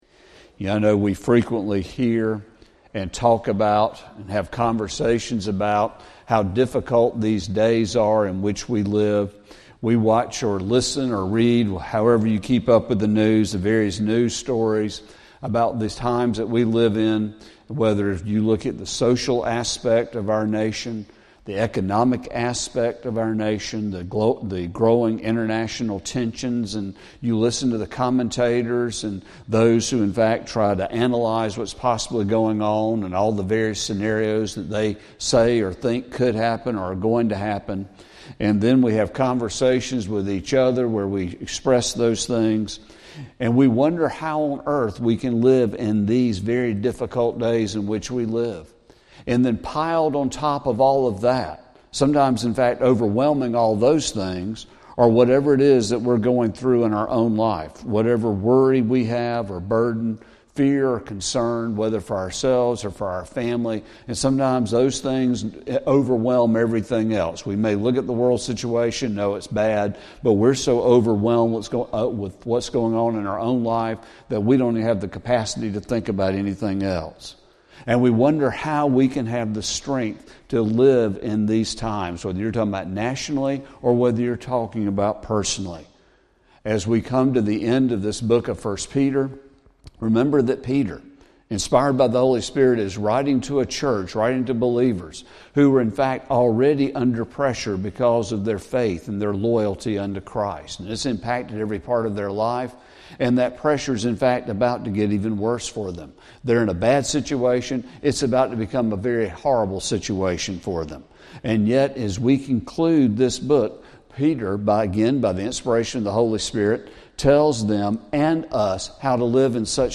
Sermon | January 19, 2025